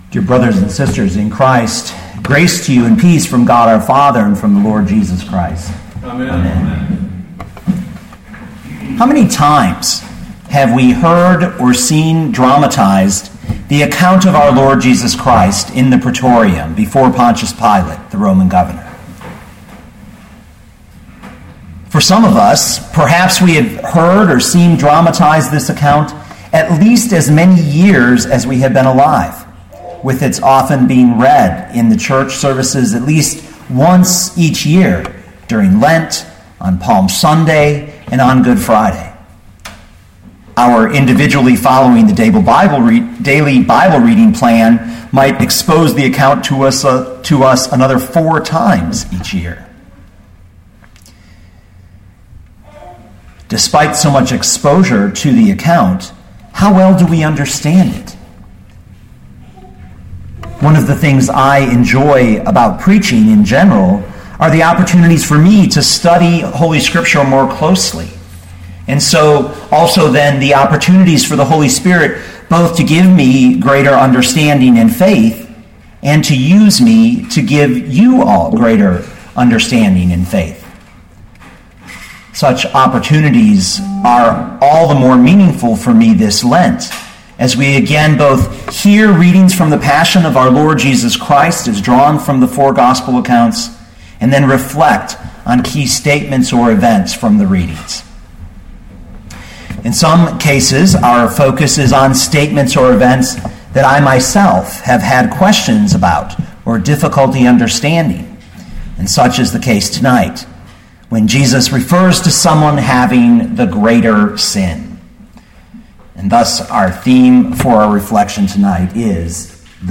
2015 John 19:11 Listen to the sermon with the player below, or, download the audio.